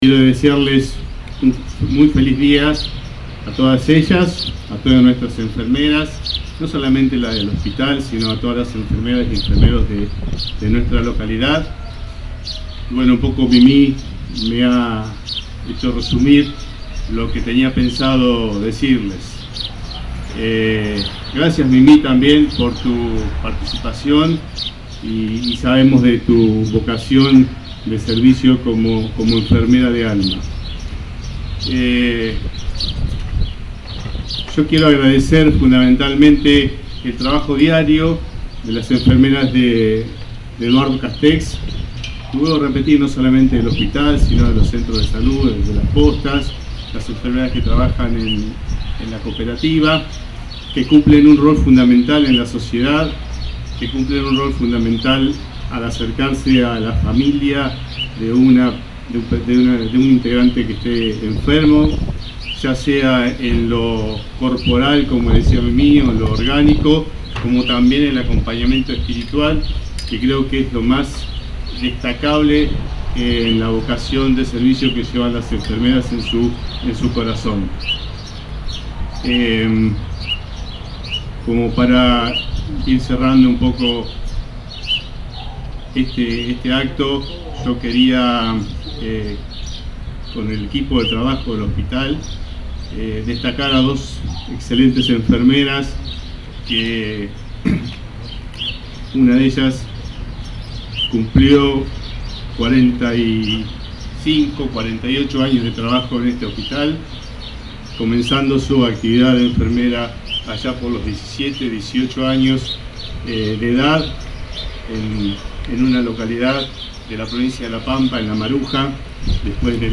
Acto por el Día de la Enfermera en Eduardo Castex